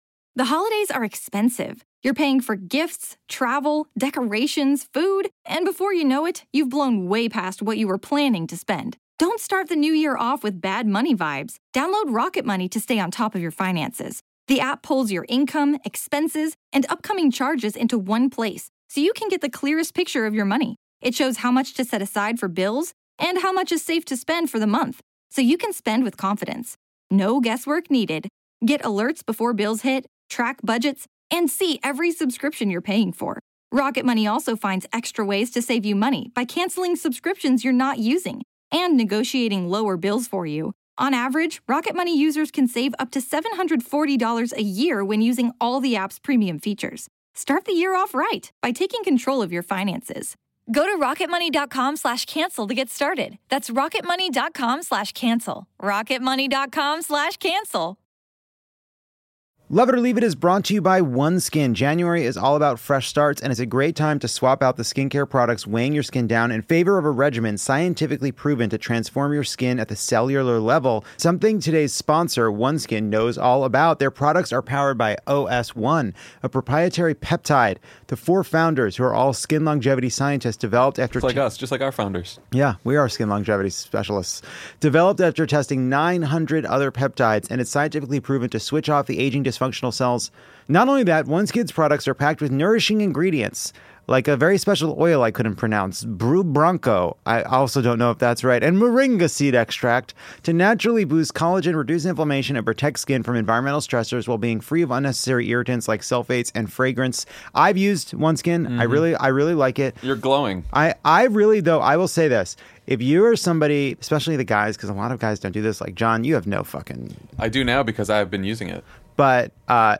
Our audience tells us their top tales of Tinseltown, and Lovett remains vigilant against the coyotes of Griffith Park.